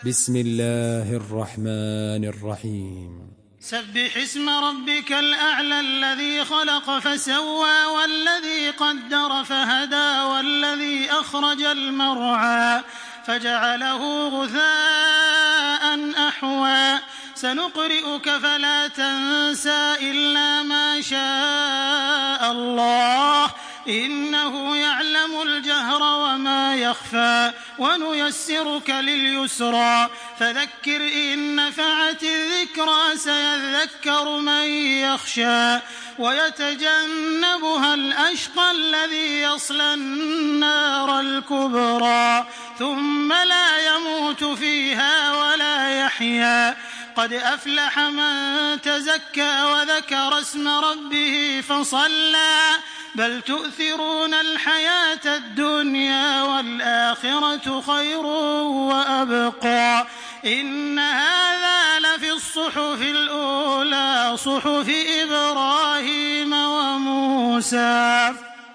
Surah Al-Ala MP3 by Makkah Taraweeh 1426 in Hafs An Asim narration.
Murattal Hafs An Asim